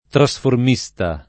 vai all'elenco alfabetico delle voci ingrandisci il carattere 100% rimpicciolisci il carattere stampa invia tramite posta elettronica codividi su Facebook trasformista [ tra S form &S ta ] s. m. e f. e agg.; pl. m. ‑sti